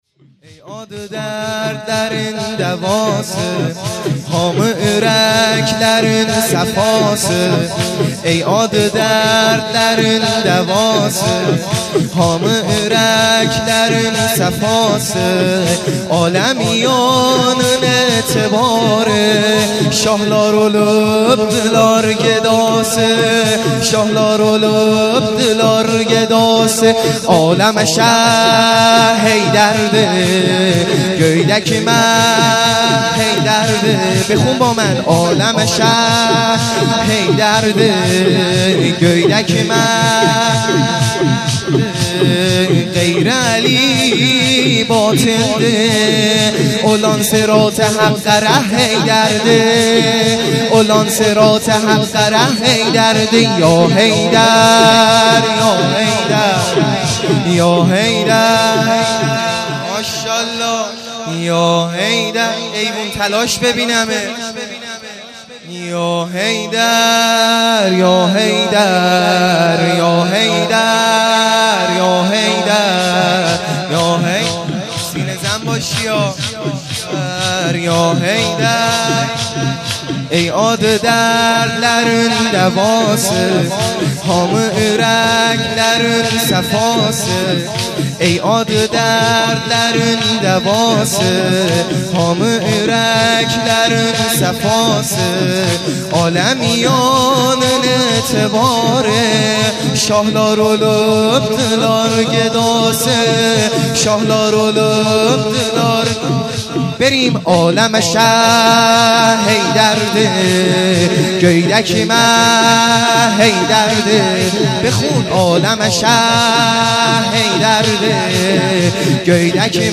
شور | ای آدی دردلرین دواسی
جلسه هفتگی ( ۱ تیرماه)